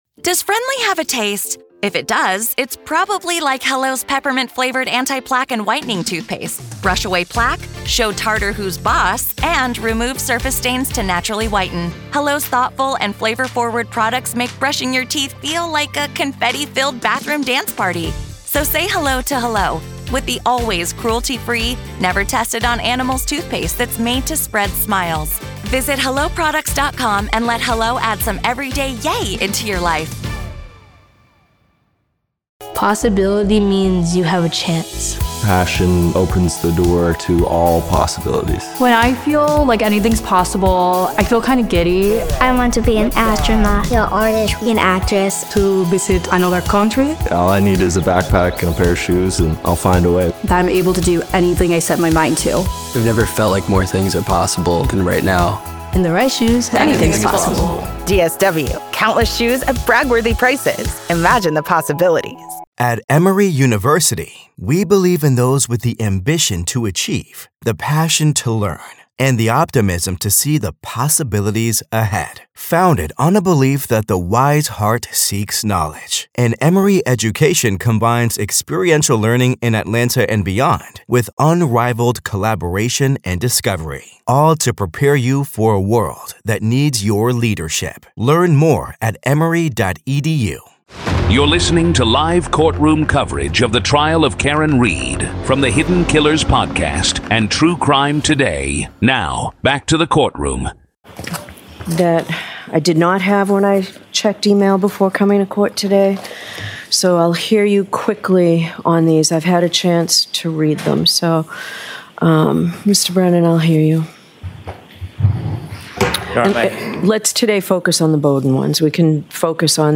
This is audio from the courtroom in the high-profile murder retrial of Karen Read in Dedham, Massachusetts. She's facing second-degree murder charges and more in connection with the death of her boyfriend, Boston Police Officer John O’Keefe. Prosecutors allege Read struck O'Keefe with her SUV and left him to die in the snow outside a friend’s home after a night of drinking.